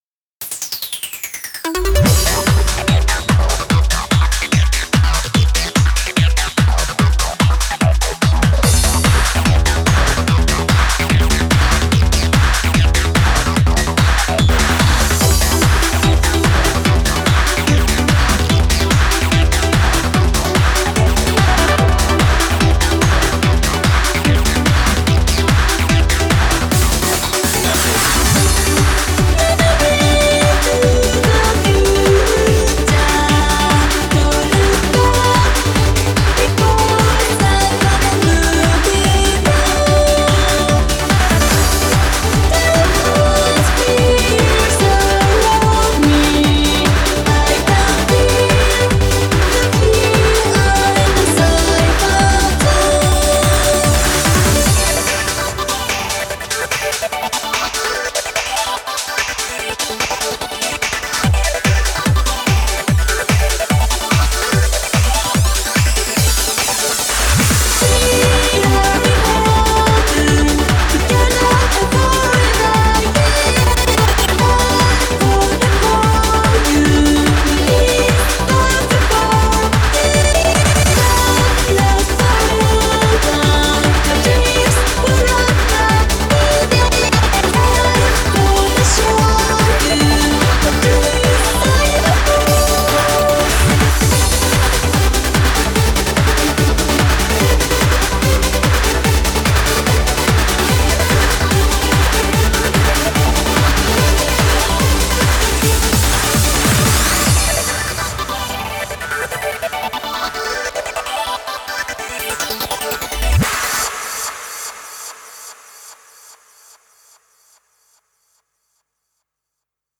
BPM146
Audio QualityPerfect (High Quality)
Genre: CYBORG TRANCE.